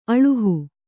♪ aḷuhu